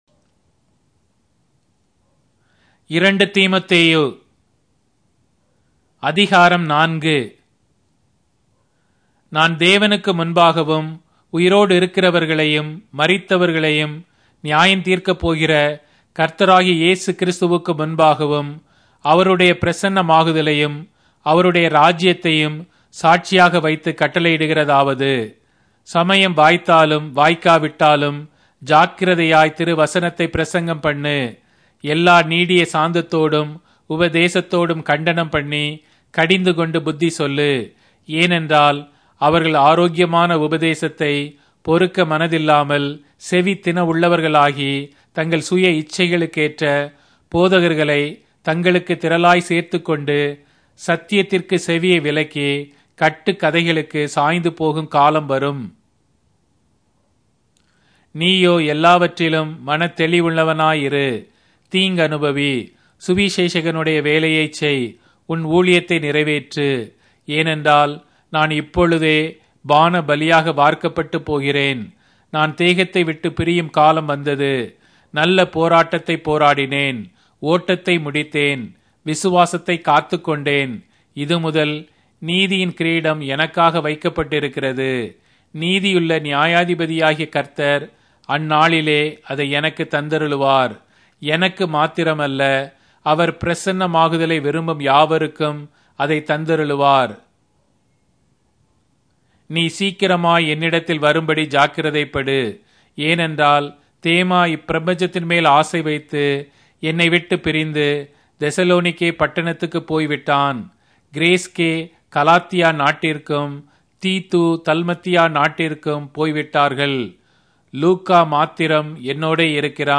Tamil Audio Bible - 2-Timothy 4 in Kjv bible version